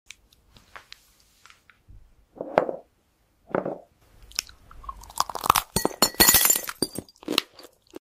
Glass Daruma Chew Sounds sound effects free download
Glass Daruma Chew Sounds AI ASMR